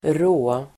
Uttal: [²'är:å:]